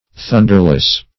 \Thun"der*less\